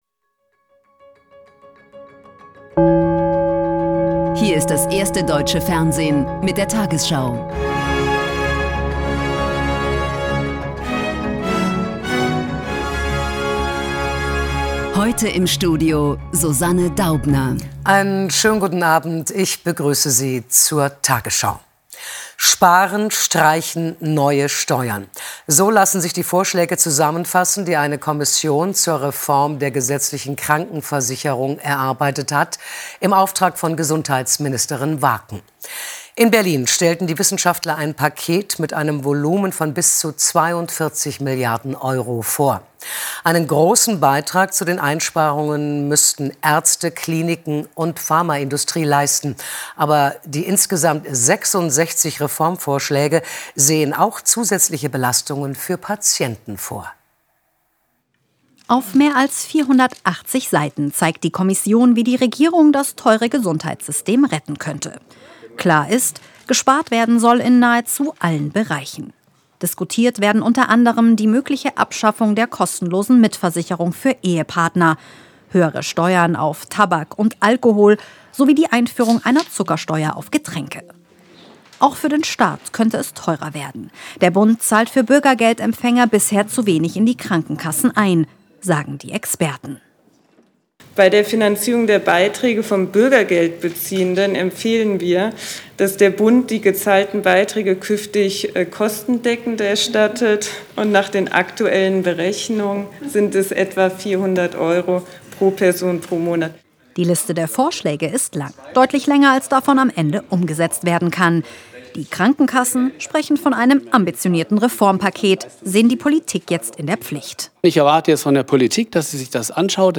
tagesschau 20:00 Uhr, 30.03.2026 ~ tagesschau: Die 20 Uhr Nachrichten (Audio) Podcast